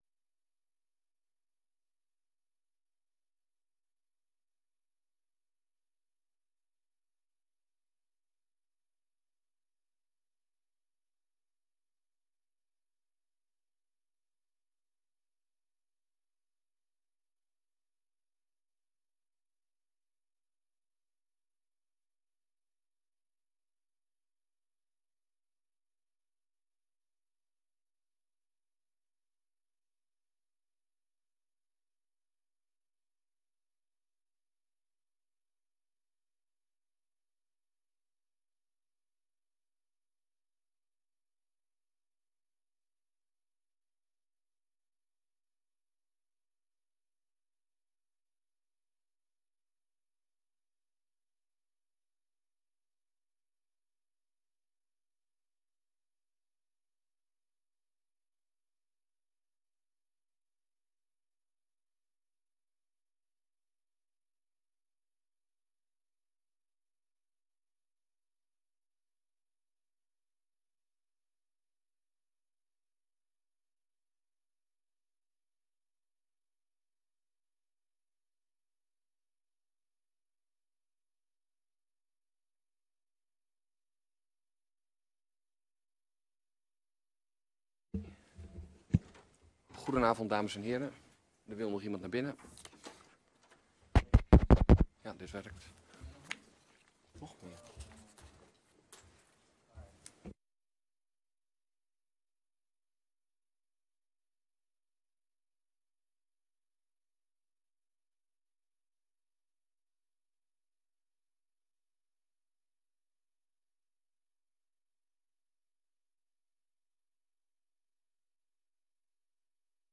Download de volledige audio van deze vergadering
Locatie: Kamer 63